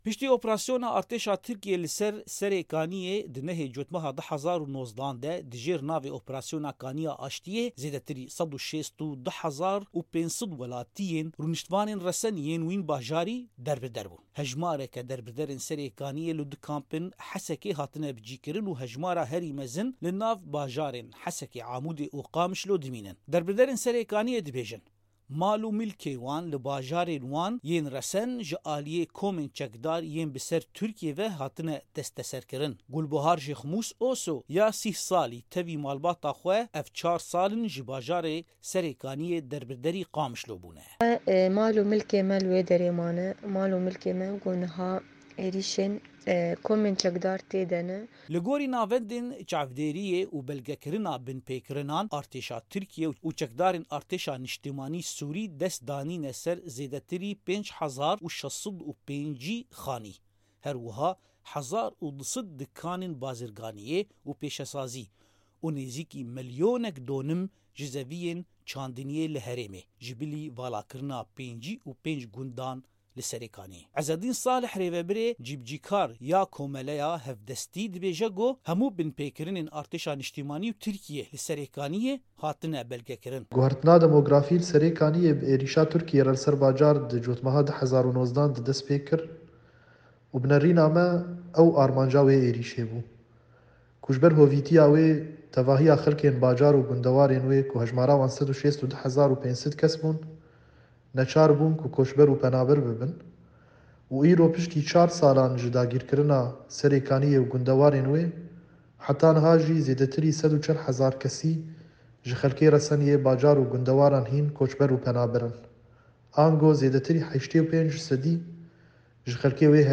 Raporta li Ser Serêkaniyê.wav